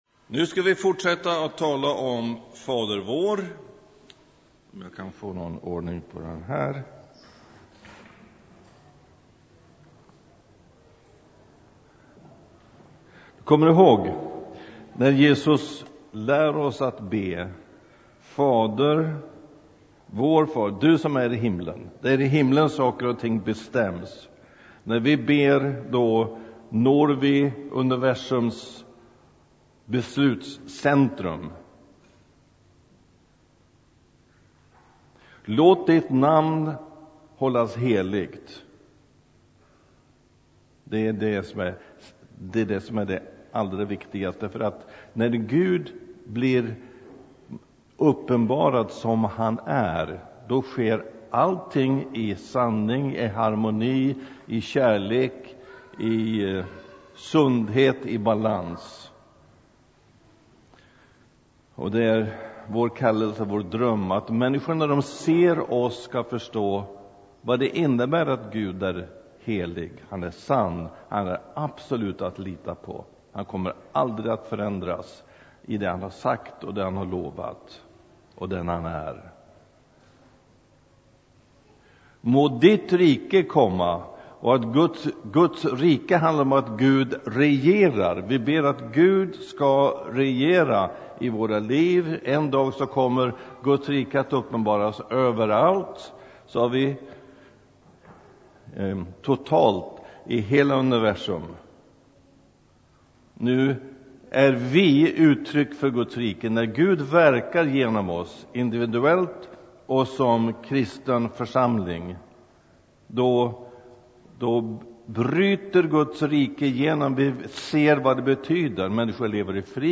undervisar.